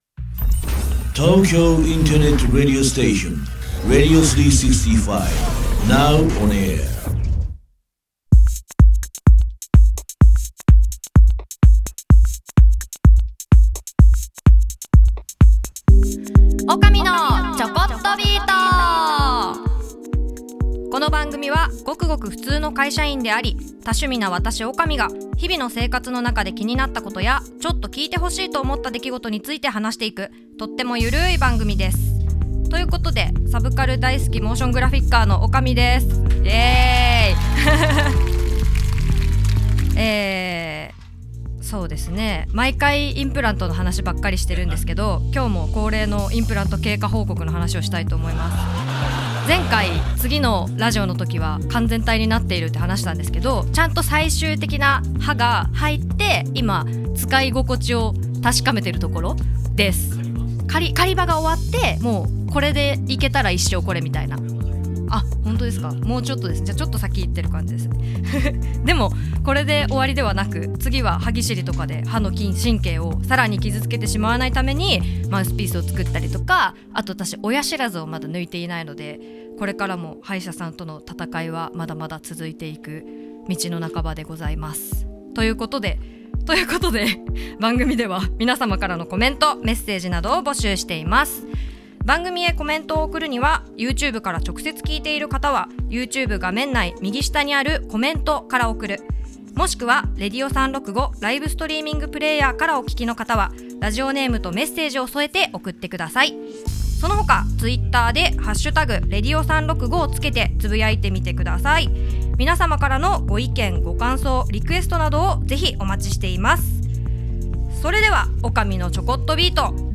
Vol.04～2022年11月20日生放送アーカイブ